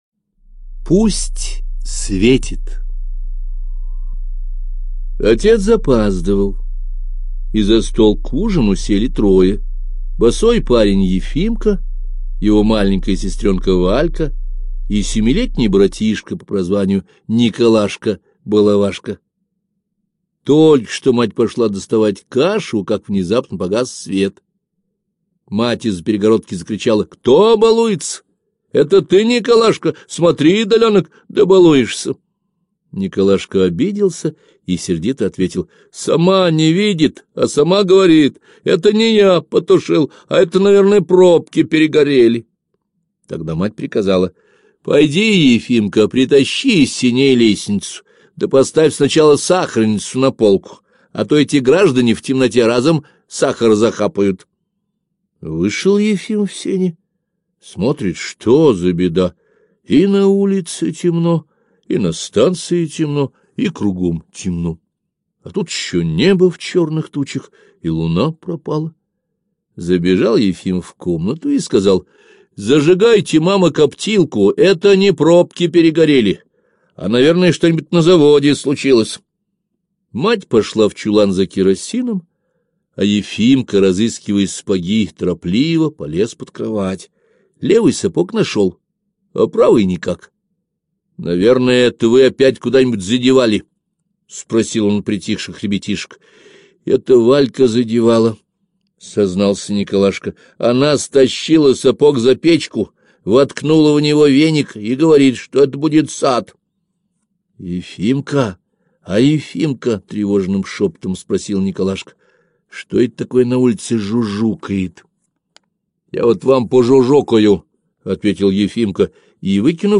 Пусть светит - аудио рассказ Гайдара А. Комсомолец Ефимка во время войны помогает красноармейцам эвакуировать мирное население.